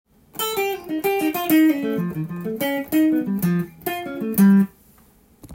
おしゃれな曲で使われる変わった響きがするスケール
Aホールトーンスケールフレーズ